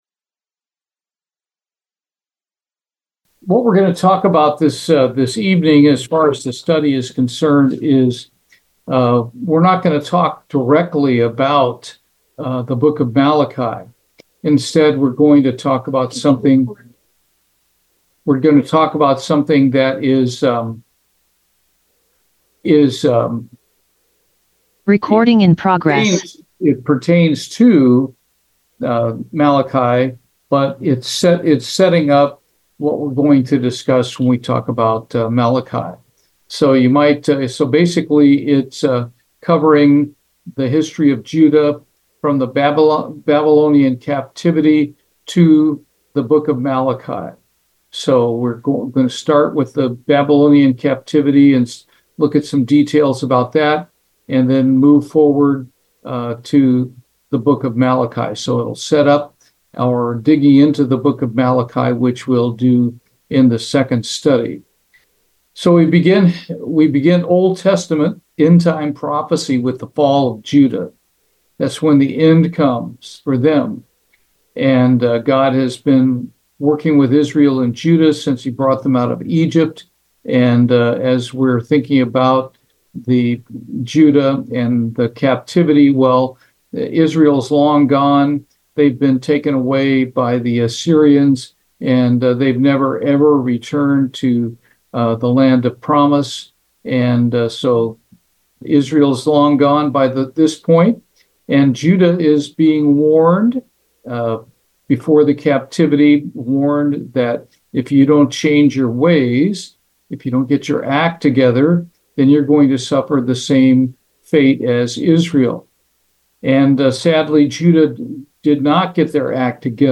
Bible Study, Malachi, Part 1
Given in Houston, TX